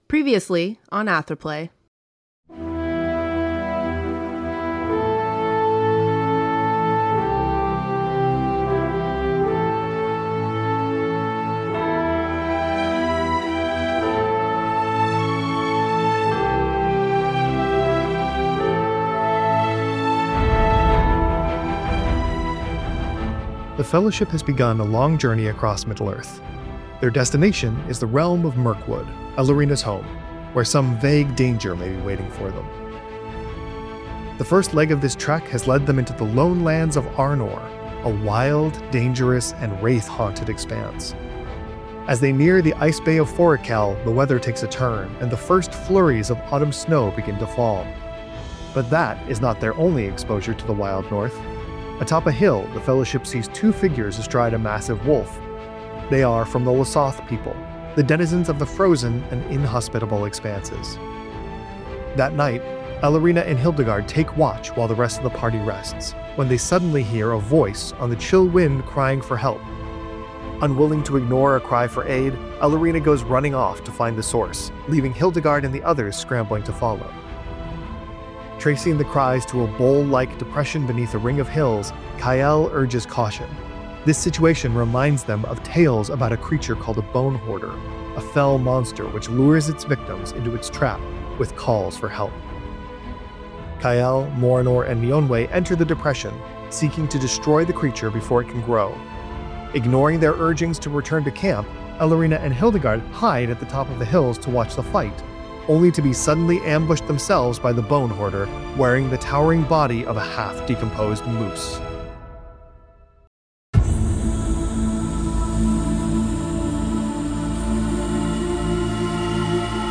Audio soundscapes by Tabletop Audio